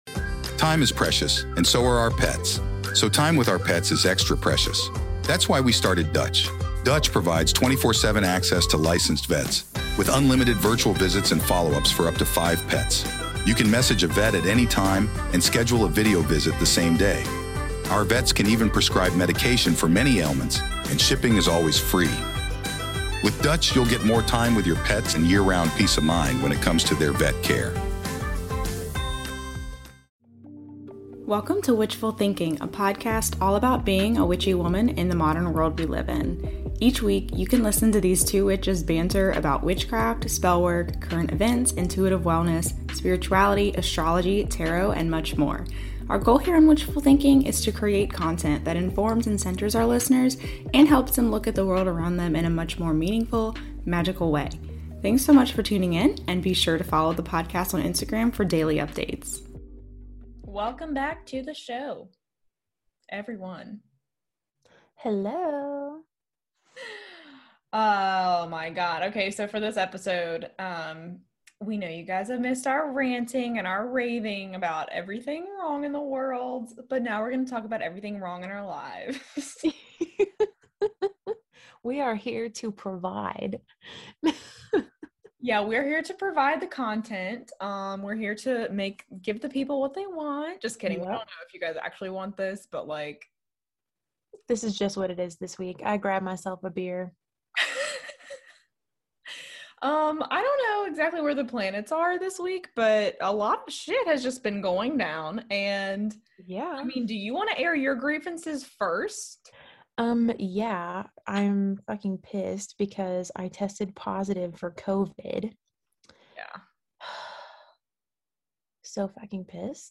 A good ole shootin' the shit episode.